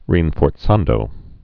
(rēnfôr-tsändō)